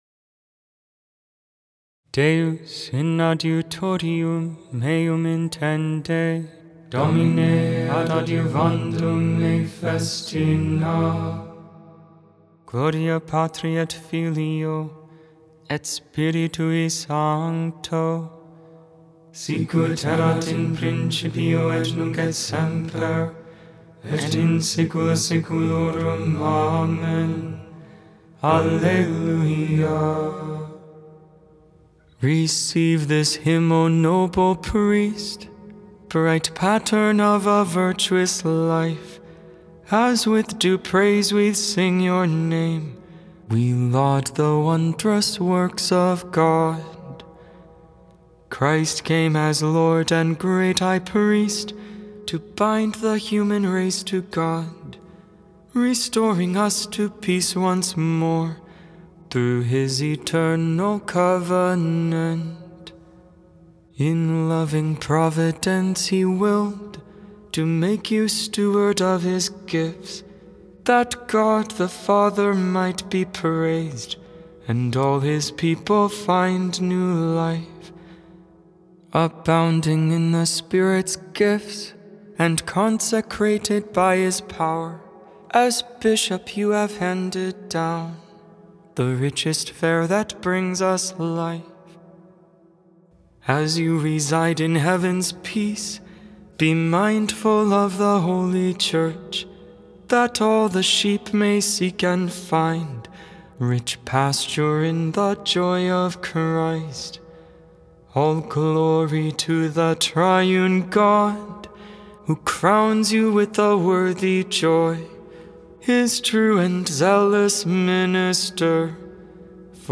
The Liturgy of the Hours: Sing the Hours 11.4.24 Vespers, Monday Evening Prayer Nov 04 2024 | 00:13:53 1x 00:00 / 00:13:53 Subscribe Share Spotify RSS Feed Share Link Embed